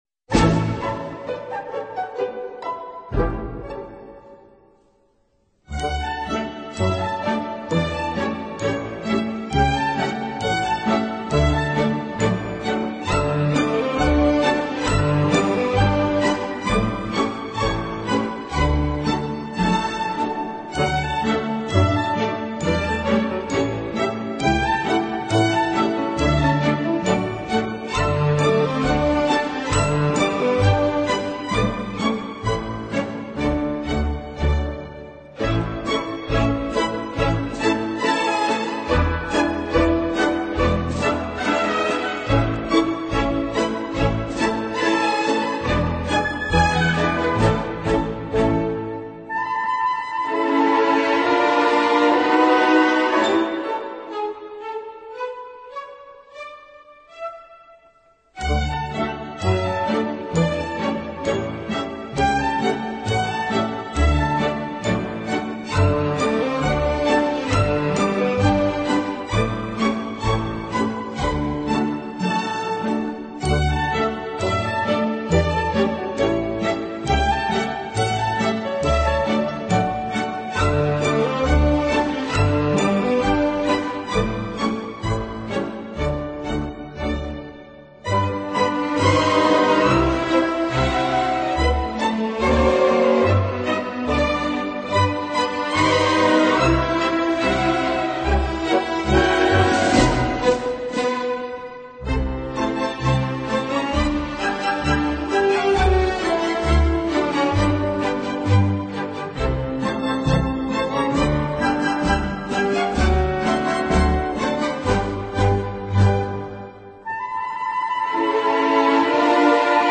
音乐类型：Classic 古典
音乐风格：Classical,Waltz